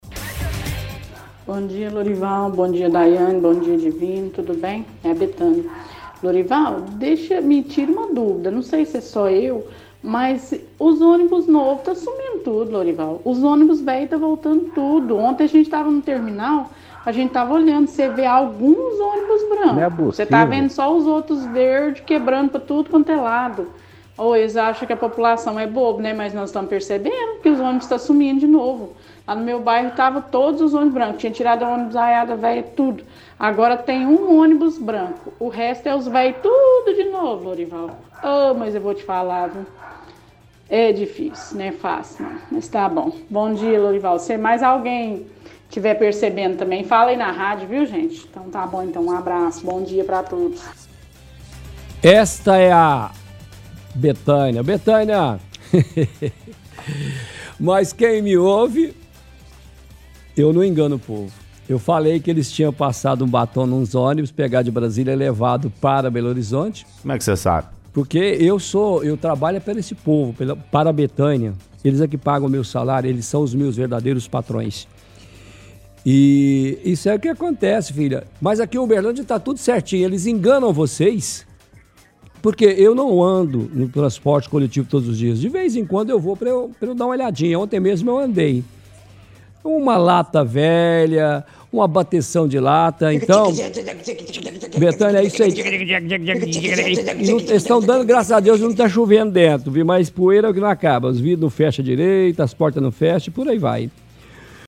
– Ouvinte reclama que ônibus antigos estão voltando a rodar com maior frequência ao invés dos mais novos.